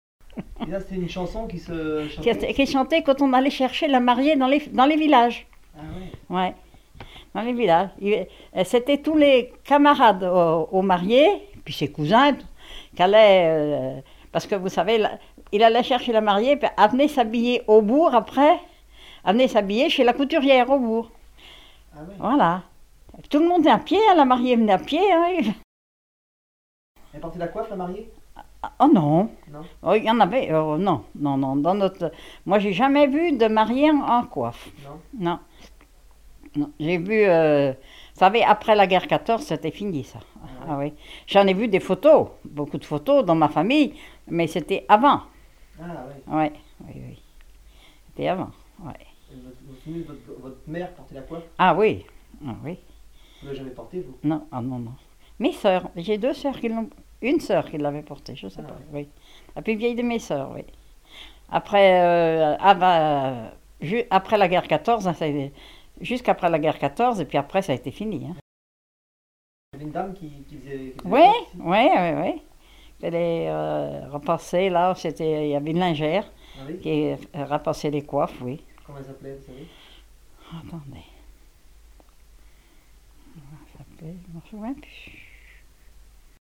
mariage rituel profane ; chanteur(s), chant, chanson, chansonnette
Treize-Vents